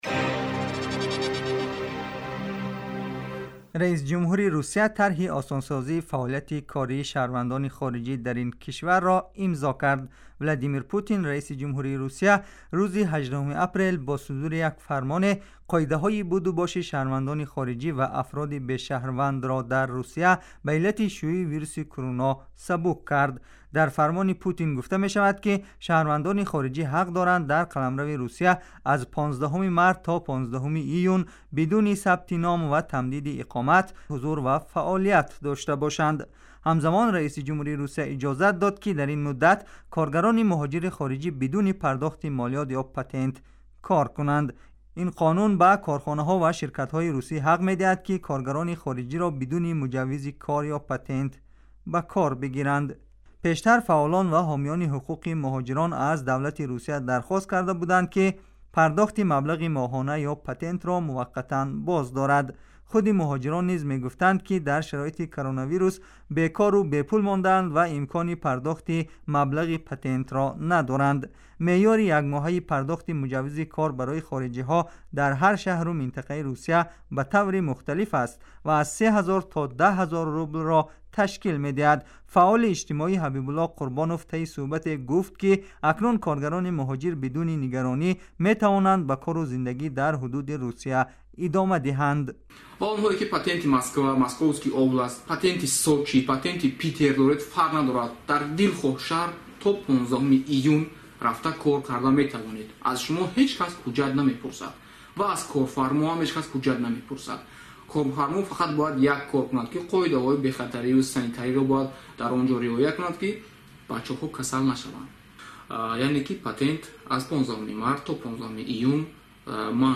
گزارش ویژه : اعطای تسهیلات به کارگران خارجی در روسیه در شرایط کرونایی